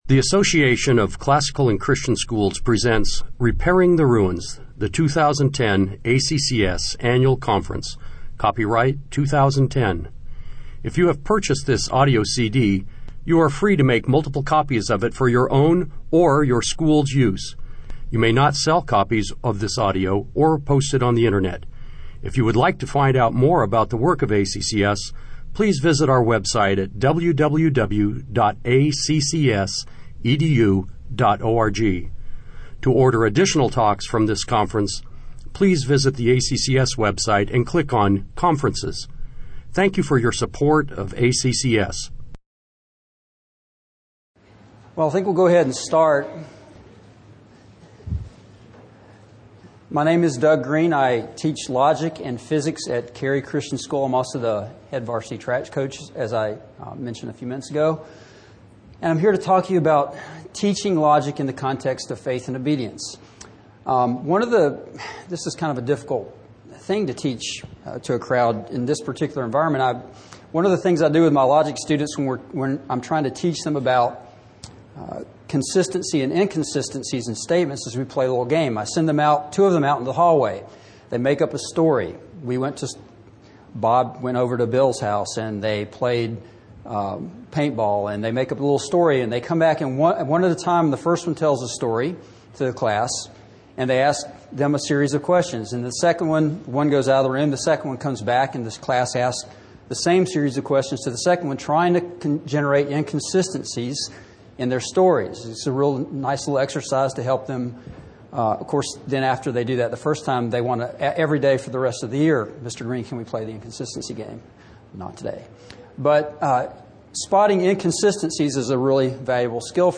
2010 Workshop Talk | | 7-12, Logic
The Association of Classical & Christian Schools presents Repairing the Ruins, the ACCS annual conference, copyright ACCS.